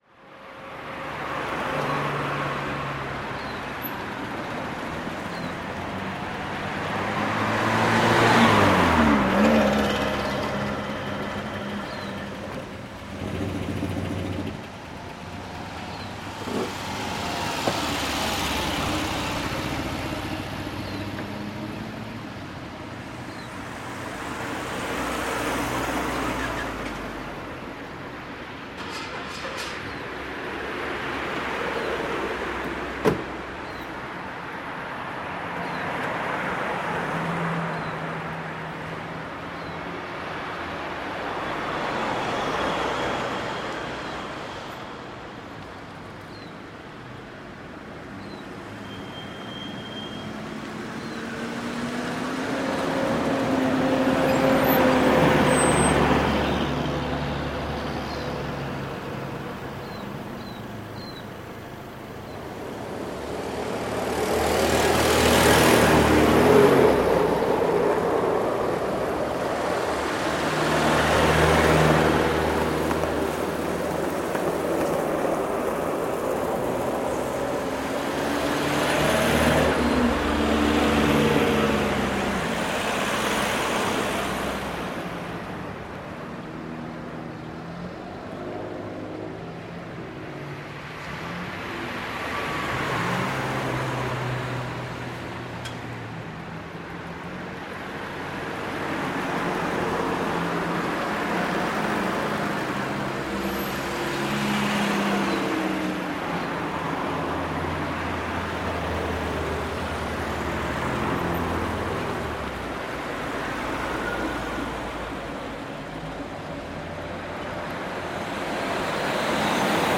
Suono atmosferico della città di Wellington in Nuova Zelanda
• Categoria: Suoni atmosferici (interferenze) della Nuova Zelanda